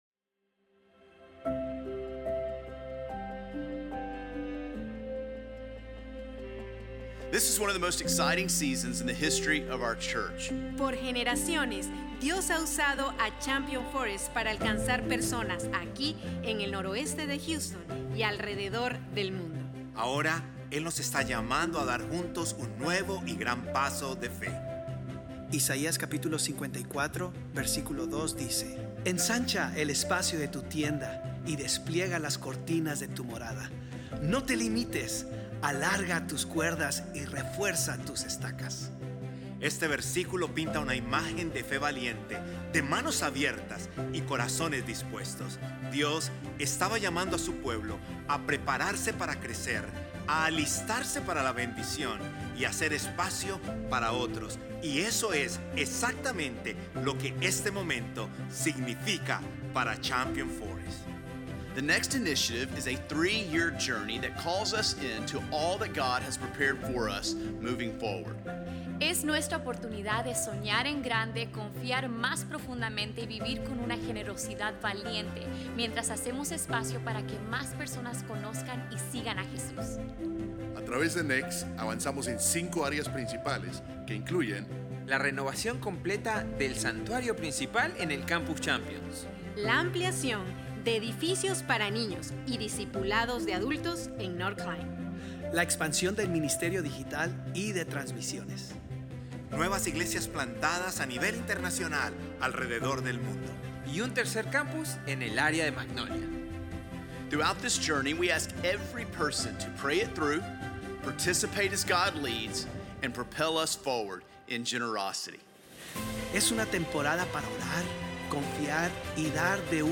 Series de Sermones – Media Player